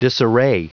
Prononciation du mot disarray en anglais (fichier audio)
Prononciation du mot : disarray